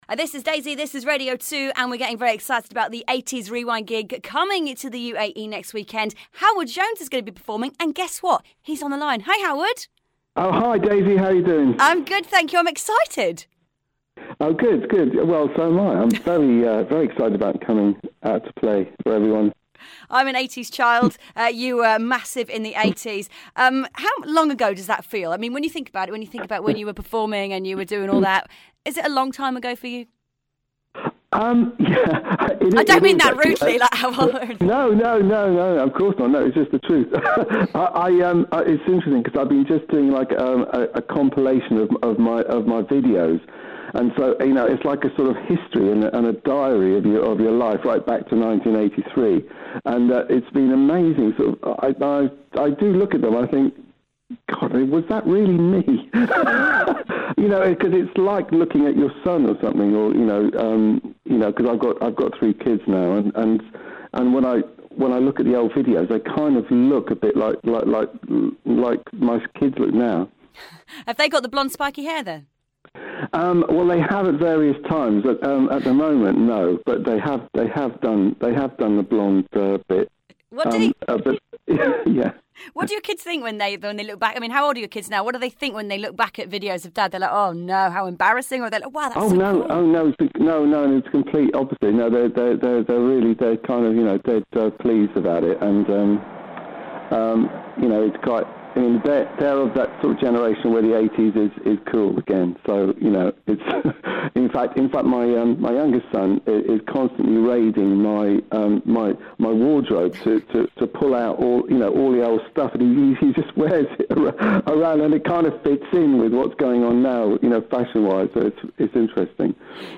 Howard Jones Interview